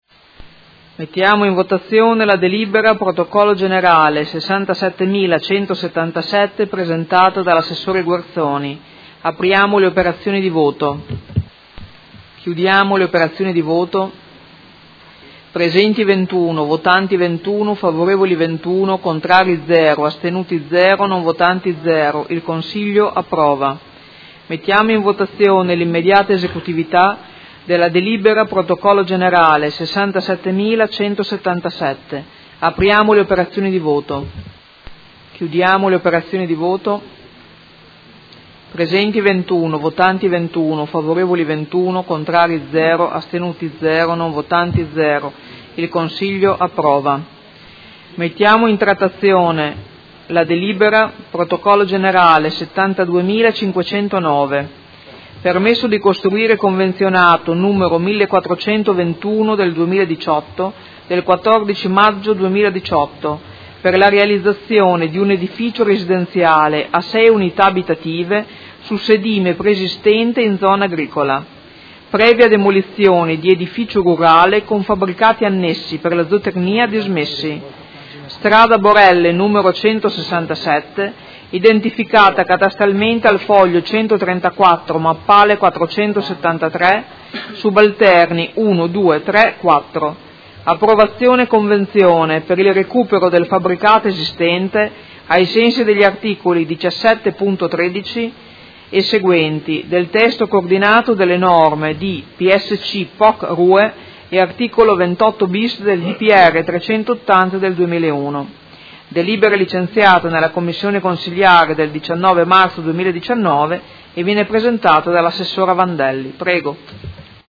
Seduta del 28/03/2019. Mette ai voto proposta di deliberazione: Costituzione di un diritto di superficie a favore del Comune di Modena delle aree di sedime dell'ex Ferrovia dismessa di proprietà RFI Spa – Linea Mi-BO nel tratto tra Cittanova e San Cataldo - Approvazione, e immediata esecutività